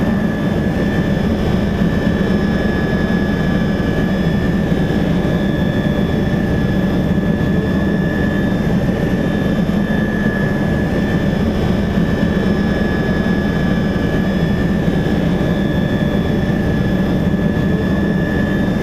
inside_L.wav